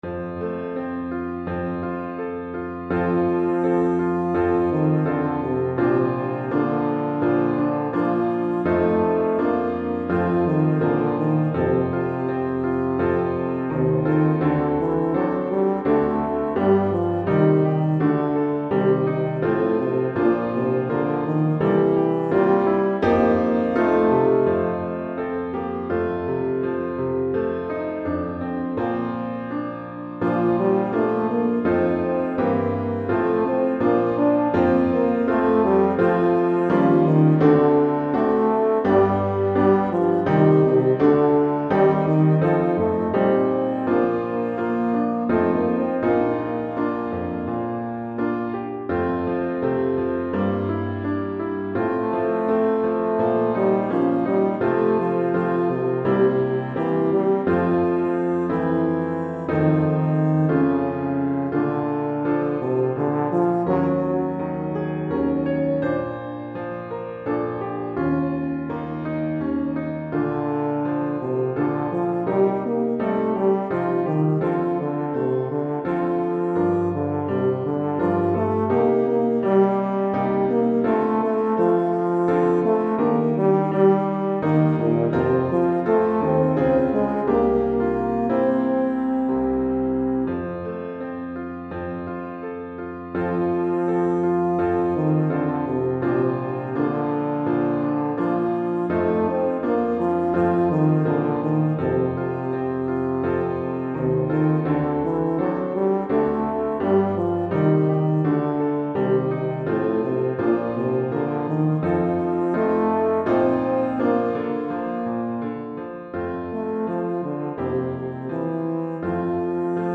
Euphonium et Piano